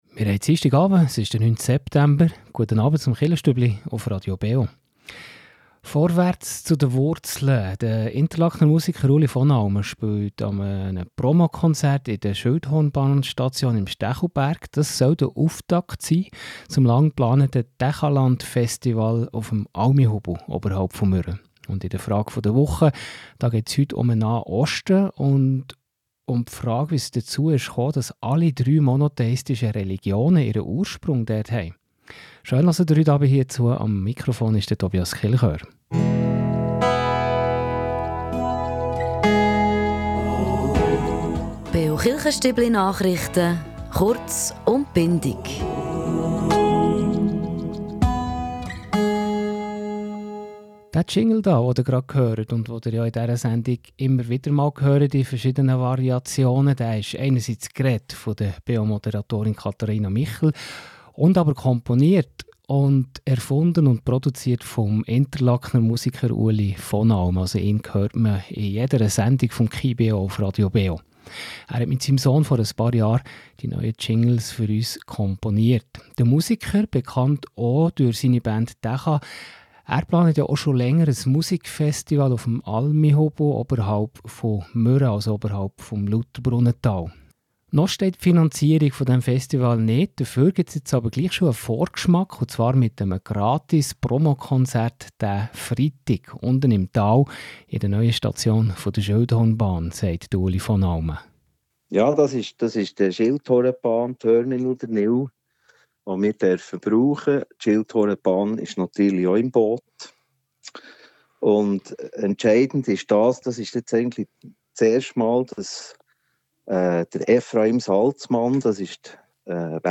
bietet mit einem Promo-Konzert im Schilthornbahn-Terminal in Stechelberg eine erste Hörprobe für das kommende Tächaland-Festival.